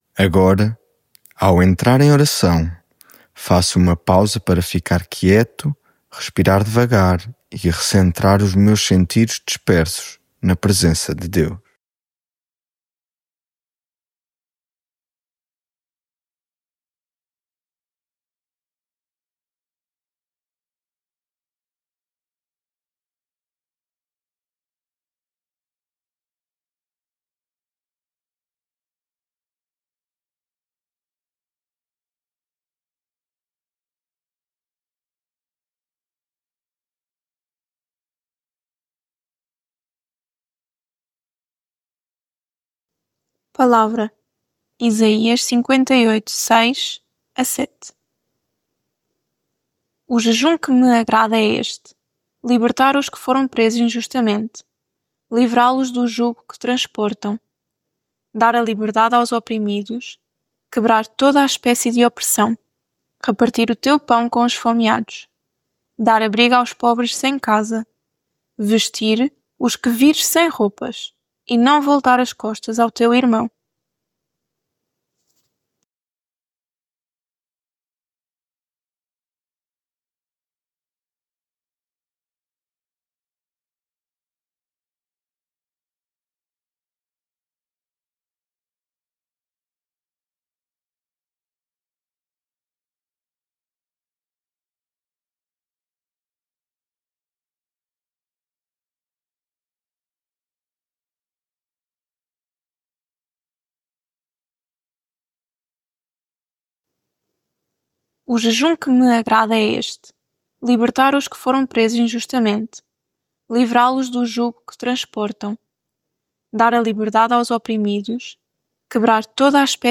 Devocional
lectio divina